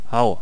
archer_ack5.wav